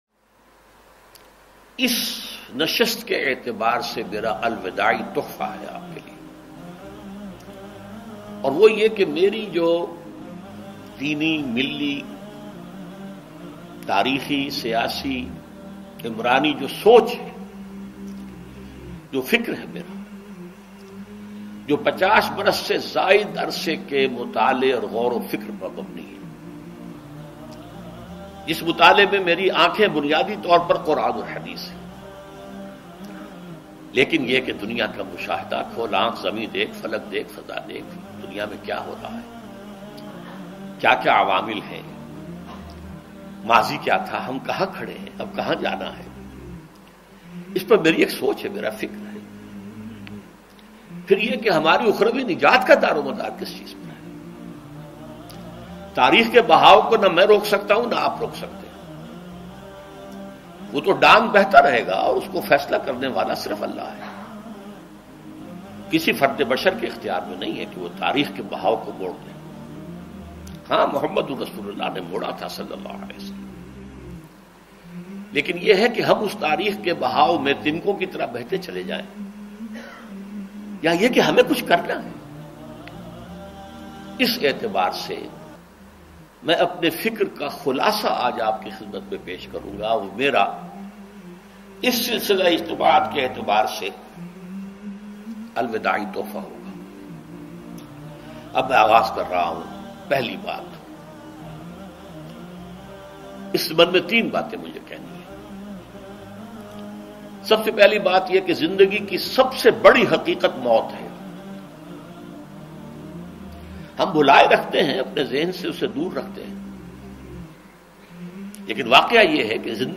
Dr Israr Ahmed R.A a renowned Islamic scholar.